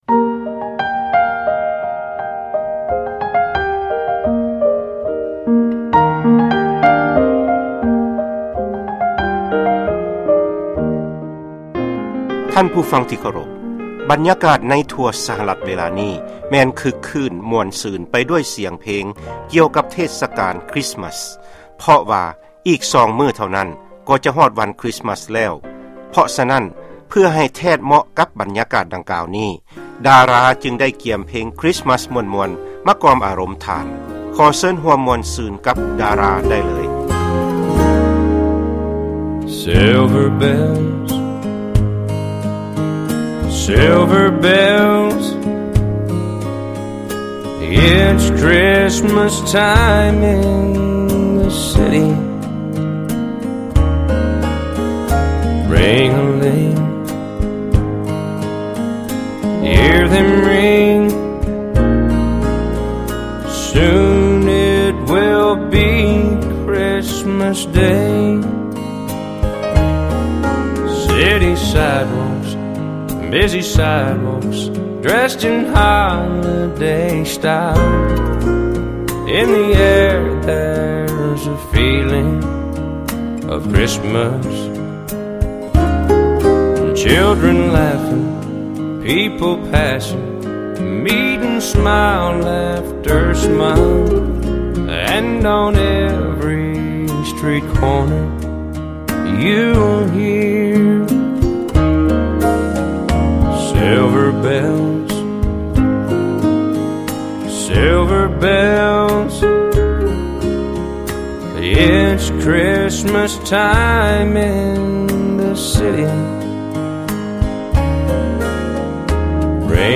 ຟັງລາຍການເພງຄຣິສມາສ